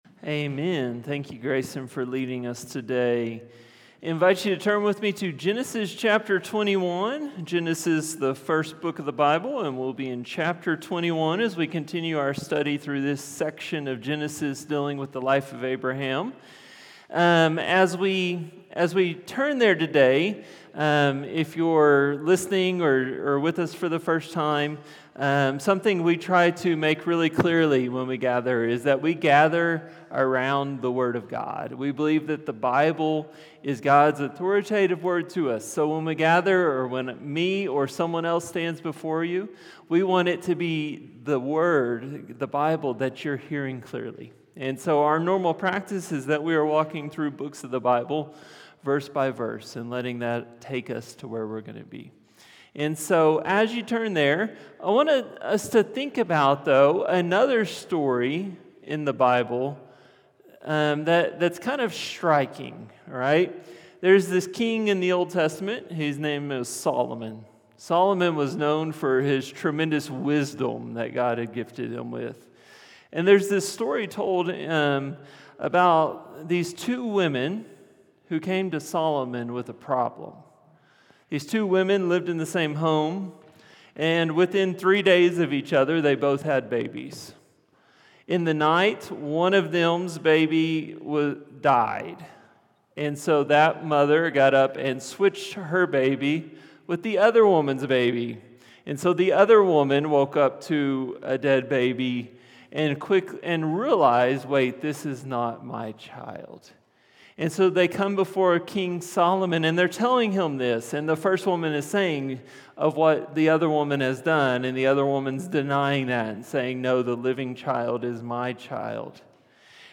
A message from the series "Genesis 12-25."